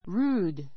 rude A1 rúːd ル ー ド 形容詞 無礼な , 無作法な, 粗野 そや な, 乱暴な a rude reply a rude reply 無作法な返事 rude manners rude manners 無作法 Don't be rude to the guests.